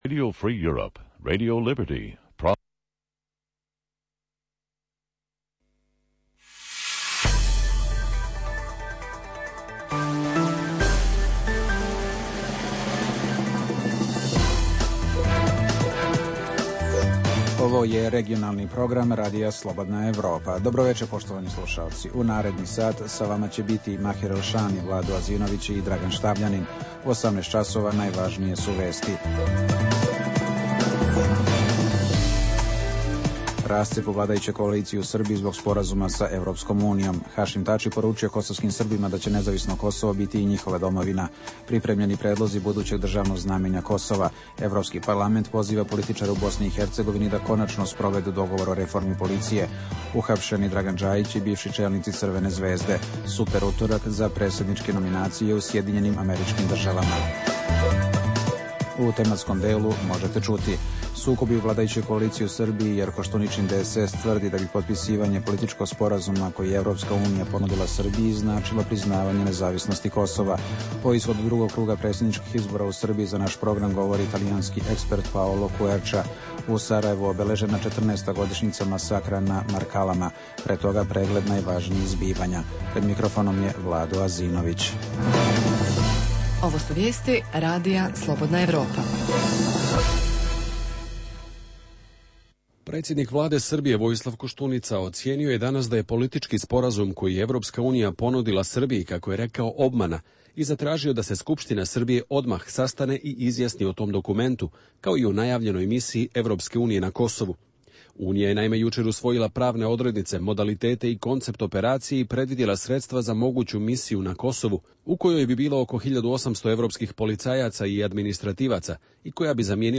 - Sve veći broj građana Hrvatske podržava članstvo u NATO-u. Intervjui sa ekspertima iz Rima i Londona